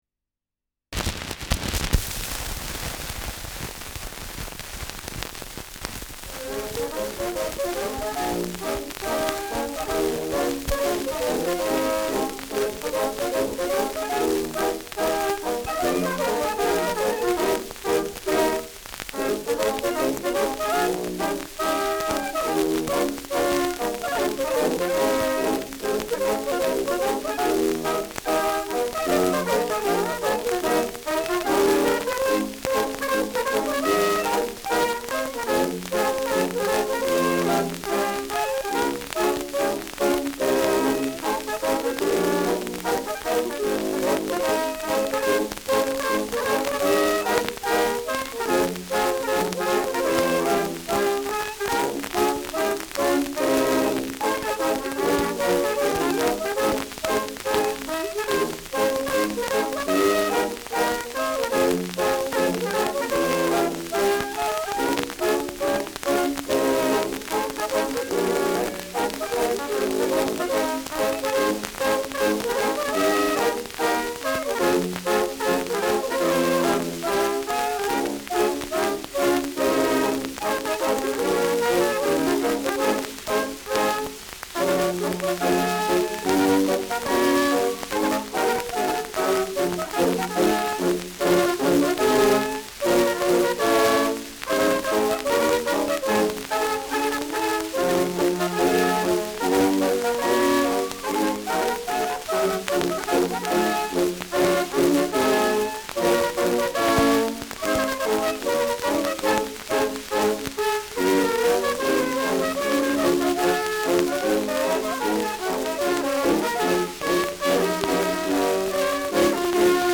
Schellackplatte
Tonrille: Kratzer Durchgehend Stärker : Kratzer 11 Uhr Stark
ausgeprägtes Rauschen